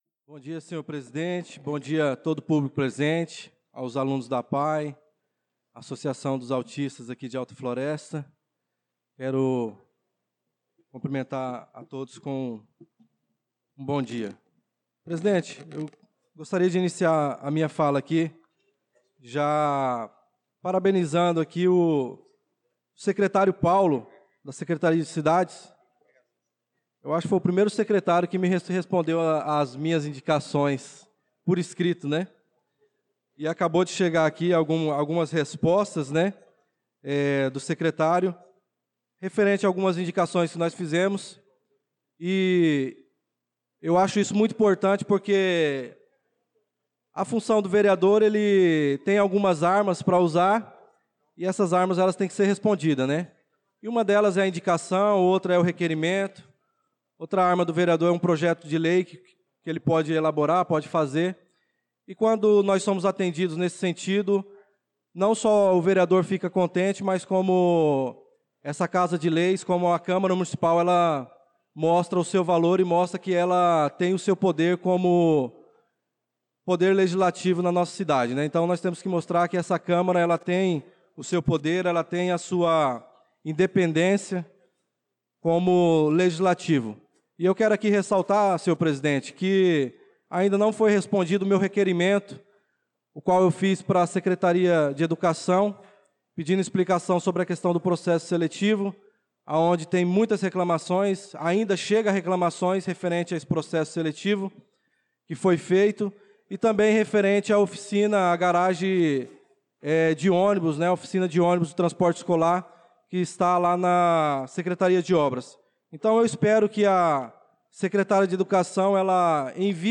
Pronunciamento do vereador Darlan Carvalho na Sessão Ordinária do dia 01/04/2025